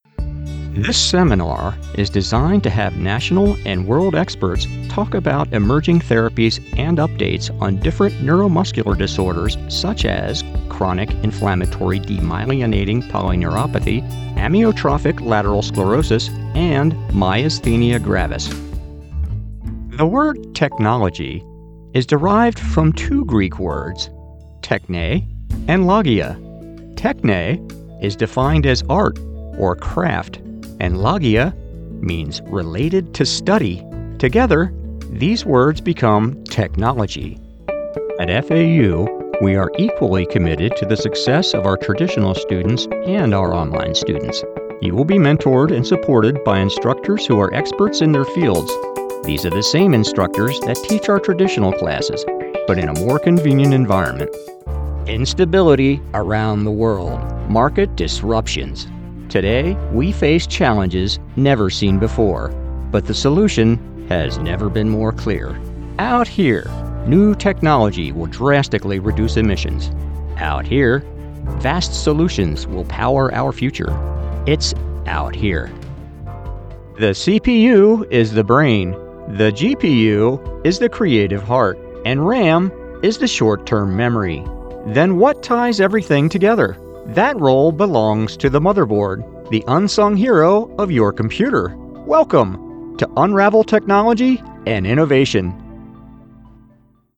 I am an American male voice actor with a rich conversational tone that delivers confidence and sincerity to your intended listeners.
Medical and Corporate narration and E-Learning
English - USA and Canada